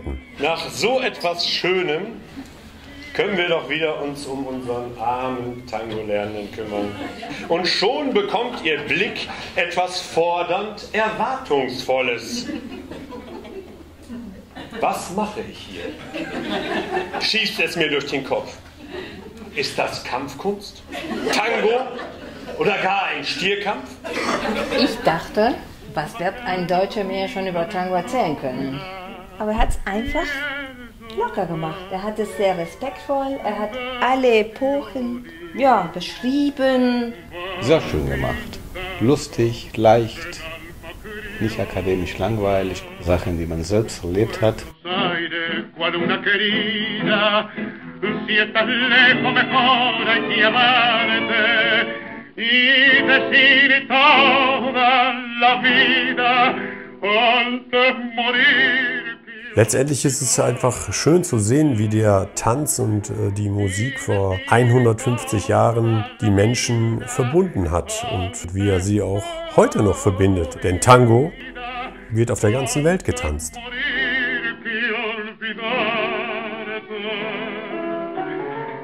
Lassen Sie sich mit dieser Lesung in die Tango-Passion entführen – die Klänge eines 100 Jahre alten Gramophons untermalen diesen unvergesslichen Abend.
Lesung mit Gramophon Musik –Die Geschichte – Geschichten – rund – um – den TANGO
Tangolesung-Ausschnitt.m4a